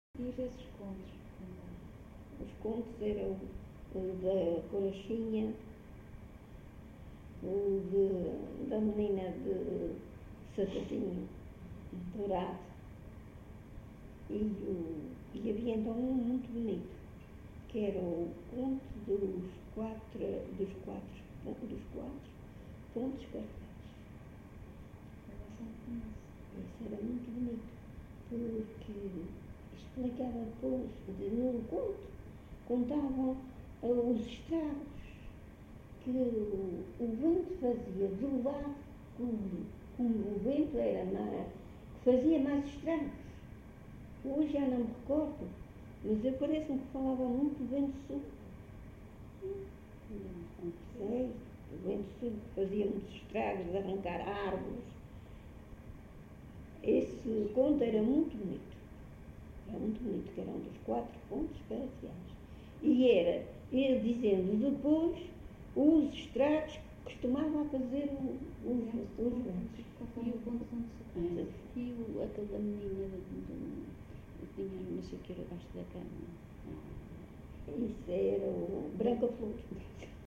LocalidadeCarapacho (Santa Cruz da Graciosa, Angra do Heroísmo)